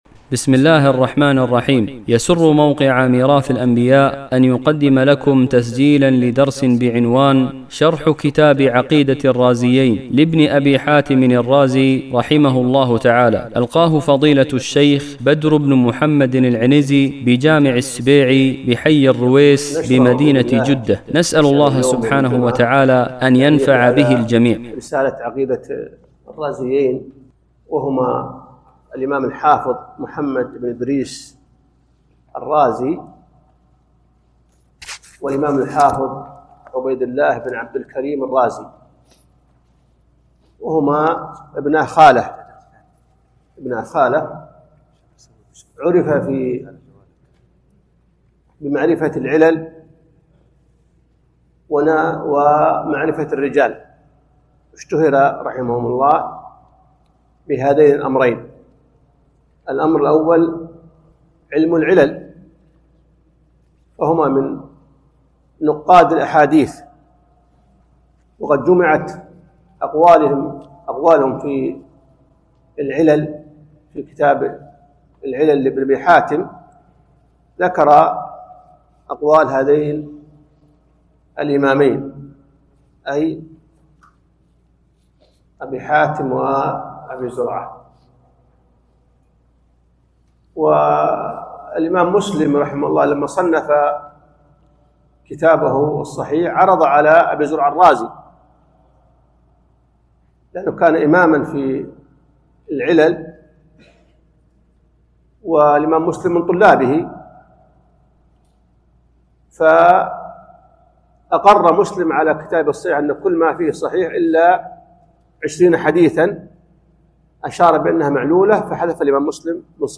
بجامع السبيعي بحي الرويس بمدينة جدة
شرح